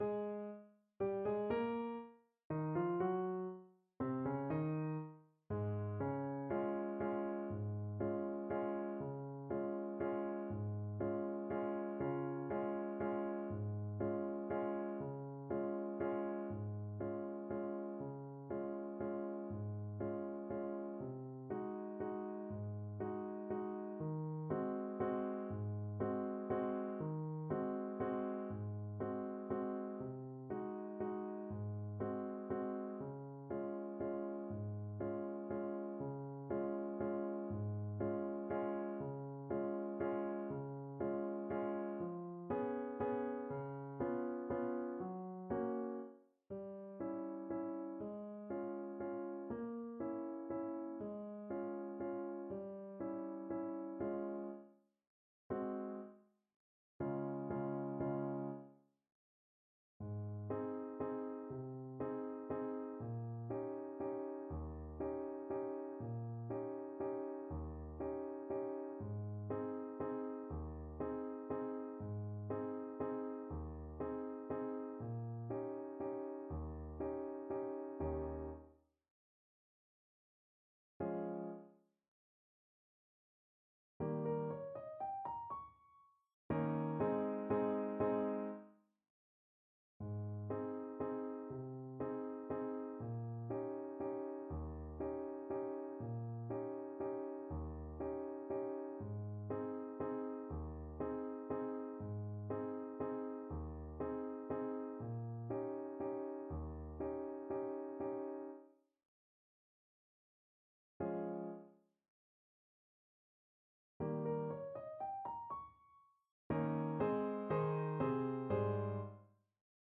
Alto Saxophone version
3/4 (View more 3/4 Music)
Tempo di Waltz (.=c.64)